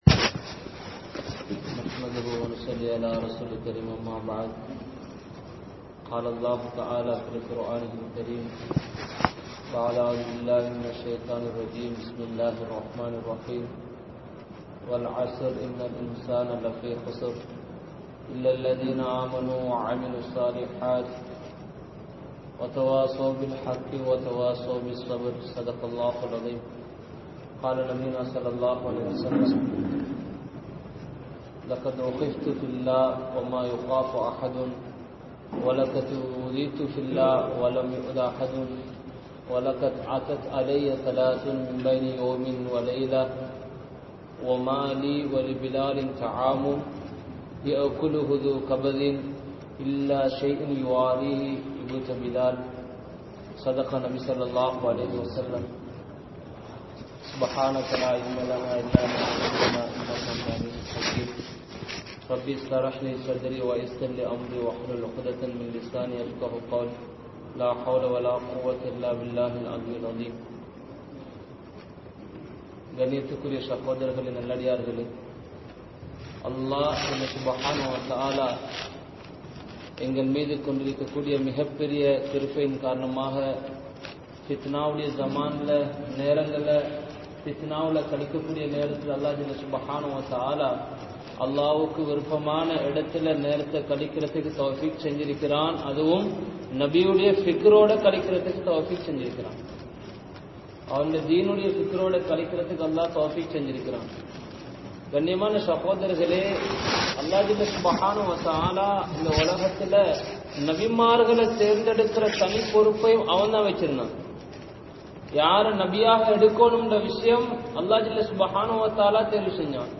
Kalimaavin Paakkiyam (கலிமாவின் பாக்கியம்) | Audio Bayans | All Ceylon Muslim Youth Community | Addalaichenai
Grand Jumua Masjidh(Markaz)